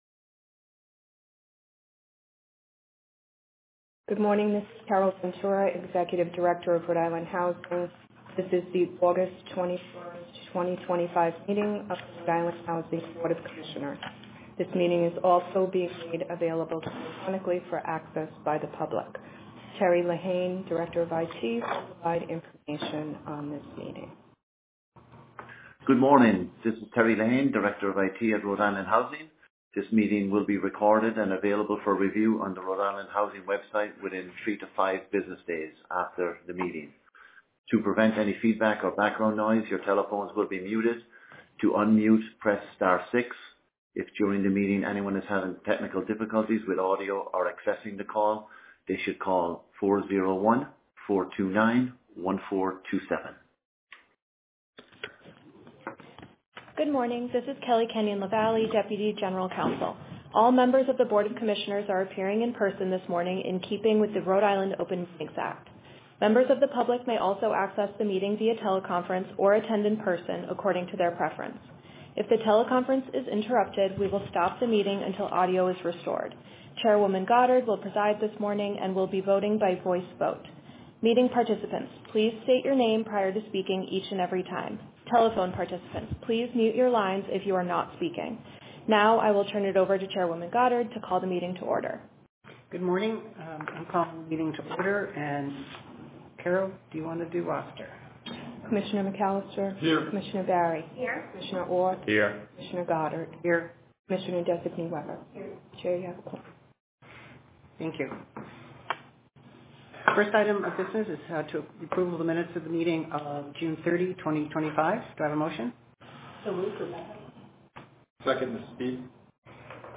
Recording of RIHousing Board of Commissioners Meeting: 8.21.2025